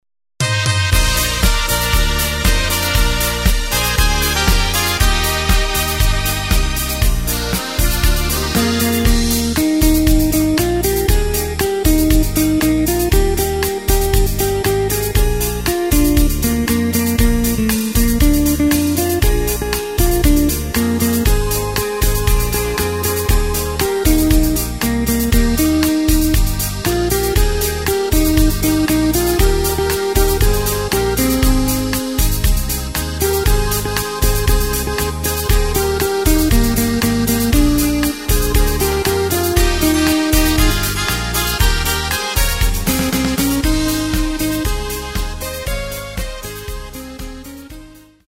Takt:          4/4
Tempo:         118.00
Tonart:            Eb
Schlager aus dem Jahr 2008!